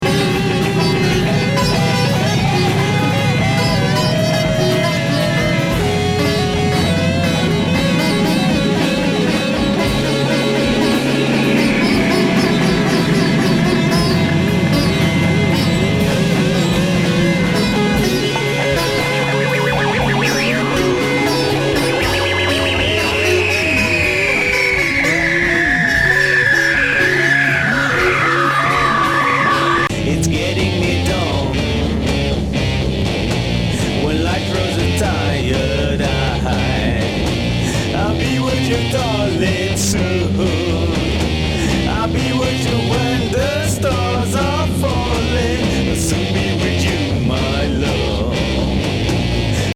カオス・エフェクト・ビョンビョン＋シタール＋闇夜ヘビーファジー・ギターな？